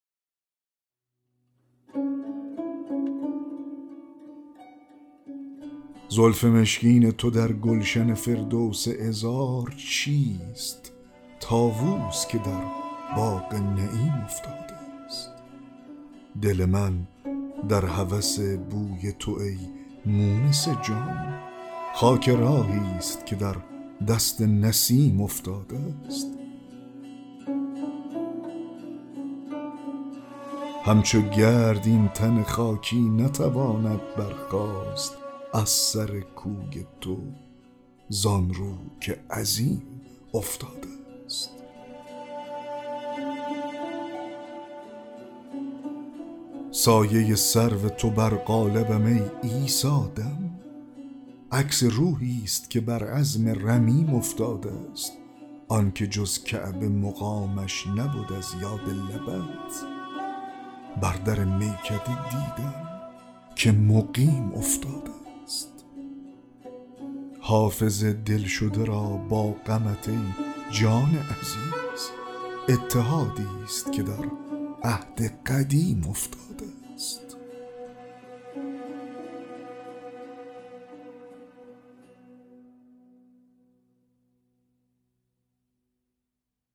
دکلمه غزل 36 حافظ